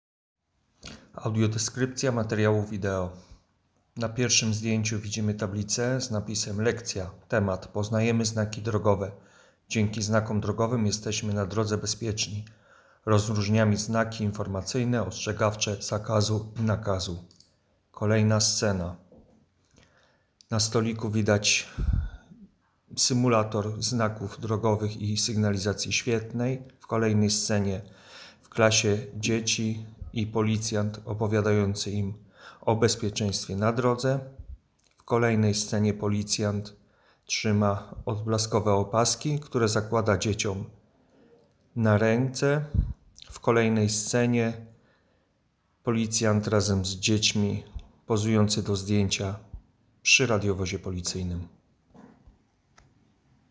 Opis nagrania: audiodeskrypcja materiału wideo